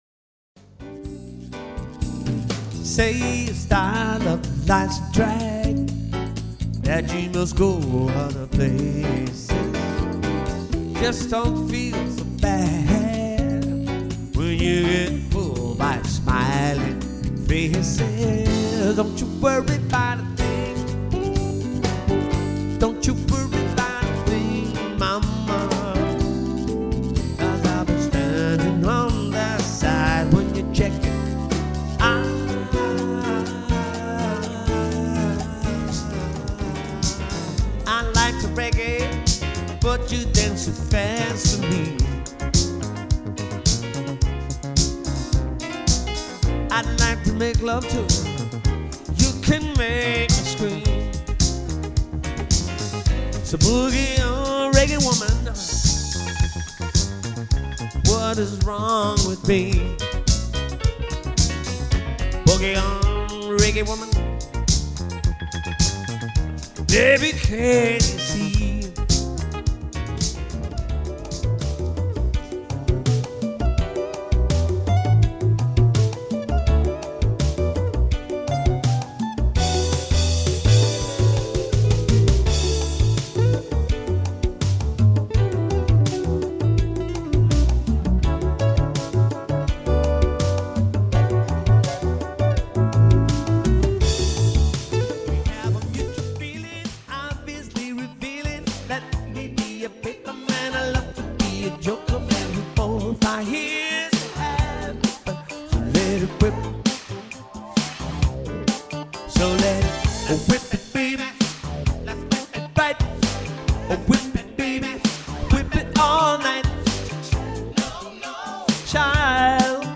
recorded direct to digital from stage performance